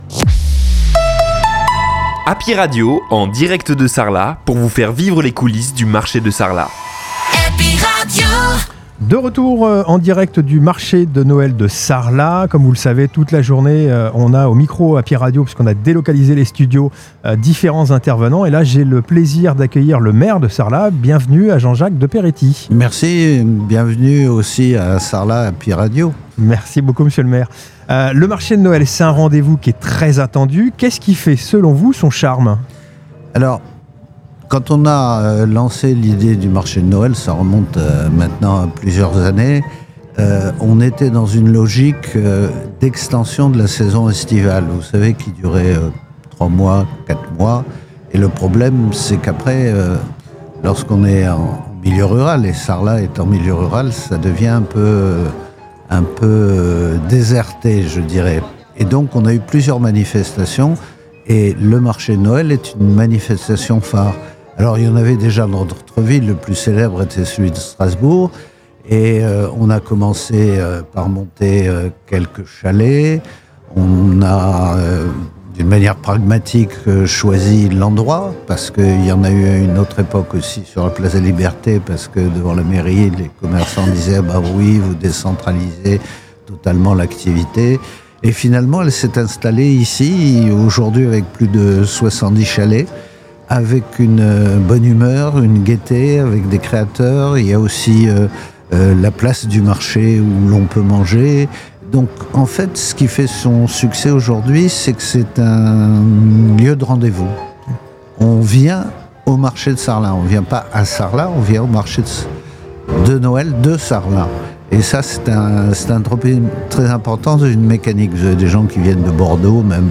Marché de Noël de Sarlat – Jean-Jacques de Peretti (Maire de Sarlat)
Inauguration Marché de Noël de Sarlat